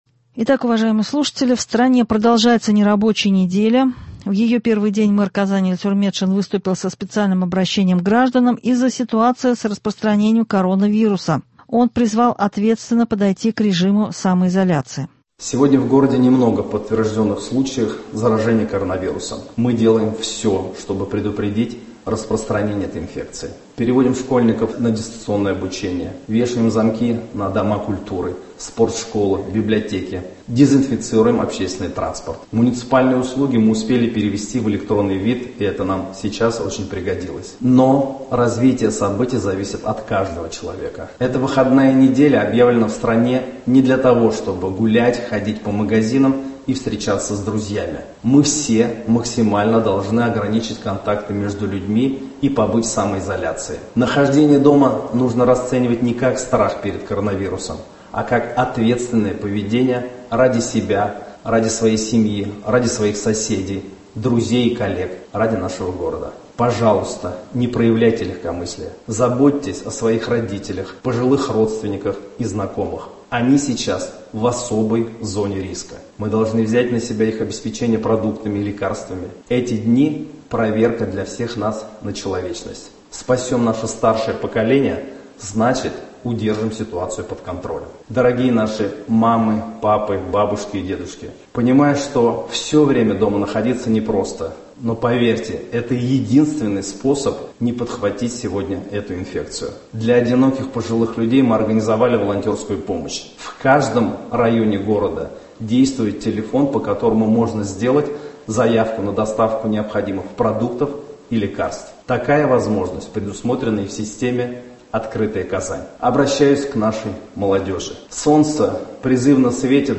Об этом сообщили на внеочередном брифинге в Доме Правительства Республики Татарстан, на вопросы журналистов ответили руководитель штаба, вице-премьер РТ Лейла Фазлеева, Руководитель республиканского управления Роспотребнадзора Марина Патяшина и глава Миндортранса РТ Ленар Сафин.